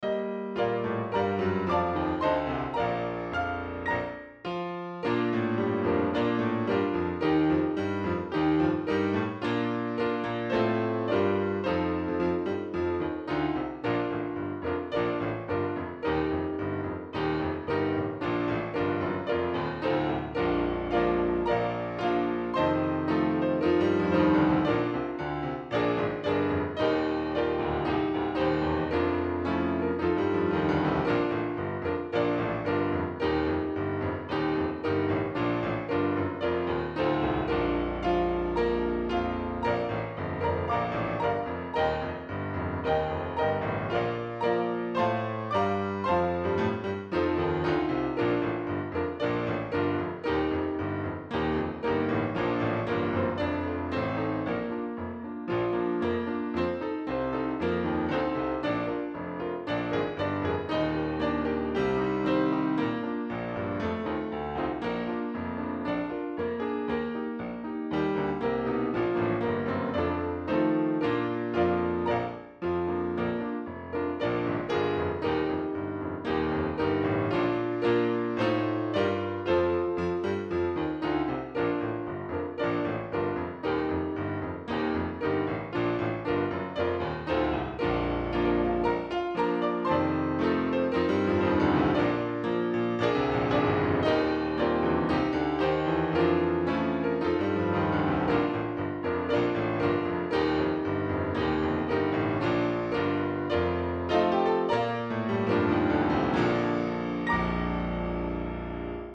Key: B♭